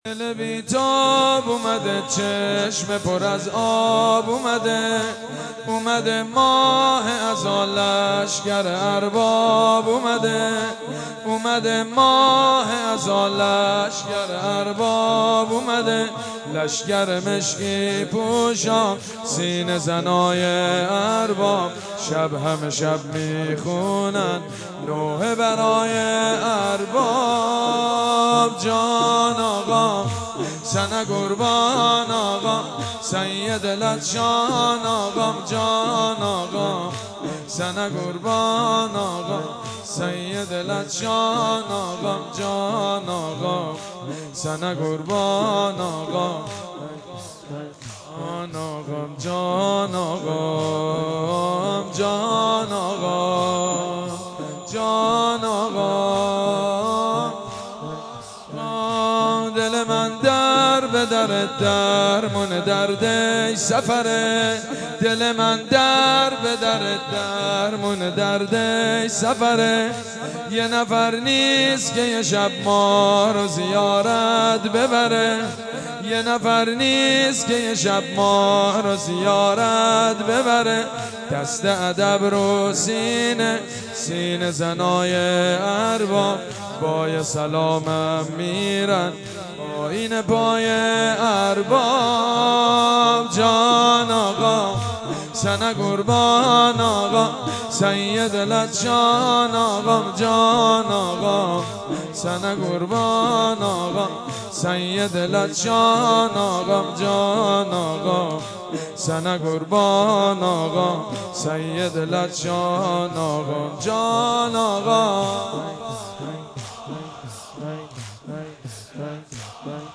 مناسبت : شهادت امام رضا علیه‌السلام
قالب : واحدشور